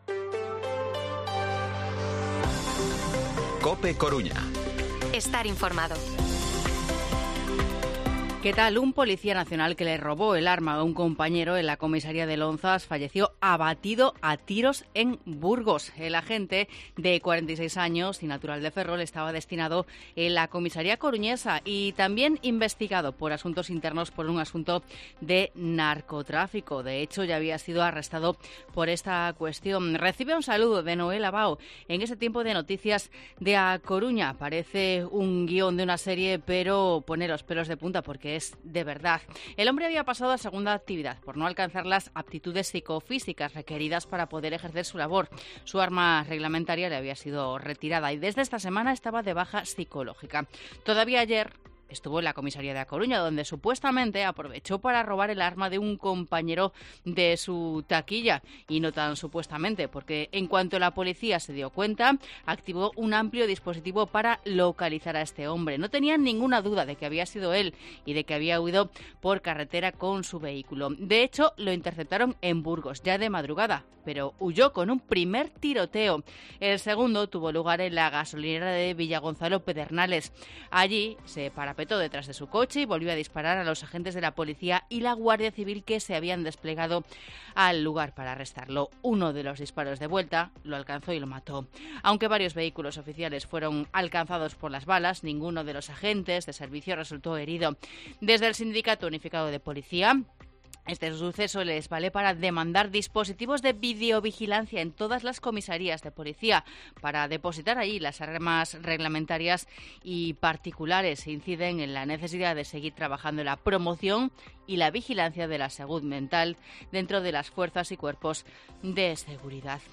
Informativo Mediodía COPE Coruña miércoles, 19 de abril de 2023 14:20-14:30